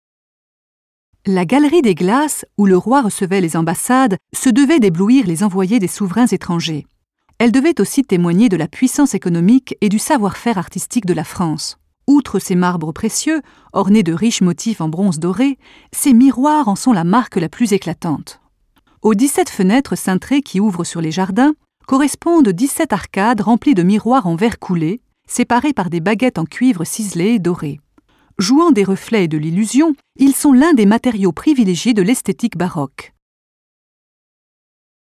VOIX-OFF AUDIOGUIDES, E-LEARNING, DOCUMENTAIRES, INSTUTIONNELS
Sprechprobe: Sonstiges (Muttersprache):